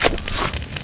PixelPerfectionCE/assets/minecraft/sounds/mob/magmacube/small5.ogg at mc116